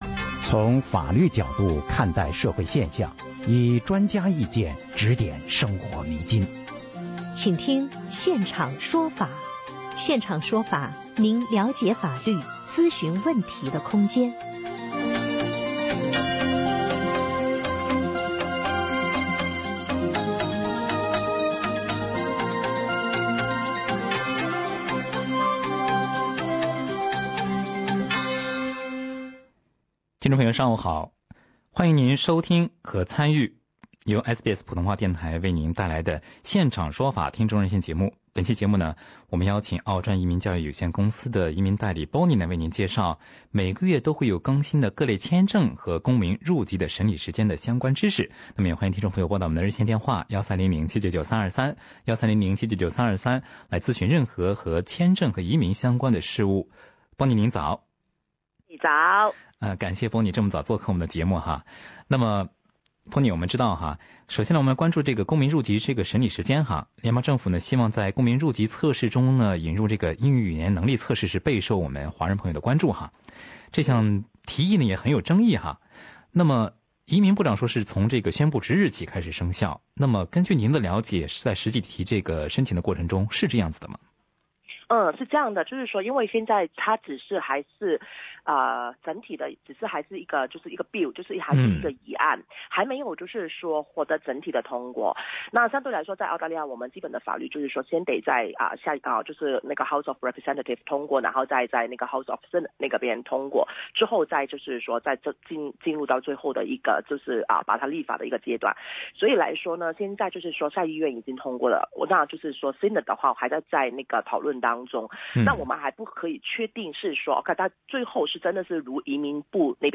citizenship application Source: SBS